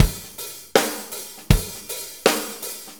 Shuffle Loop 23-10.wav